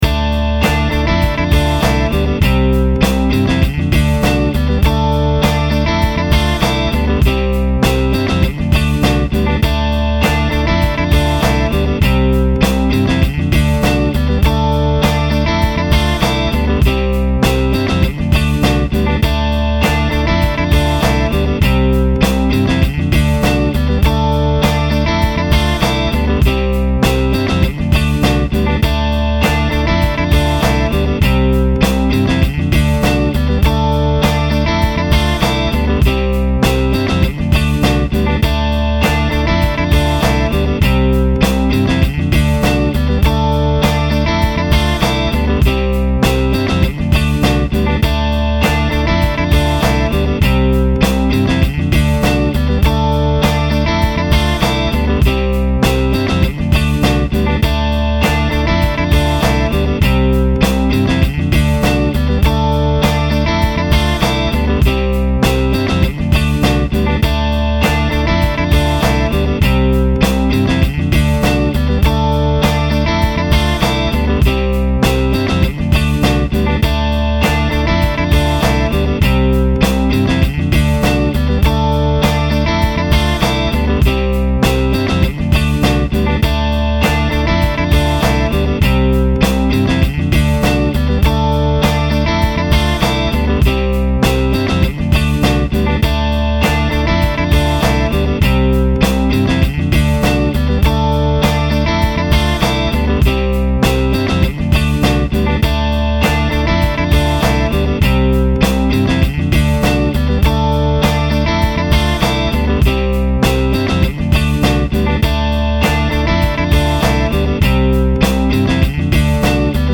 Slower Tempo: 100 bpmSlower Tempo: 100 bpm
100 Full Mix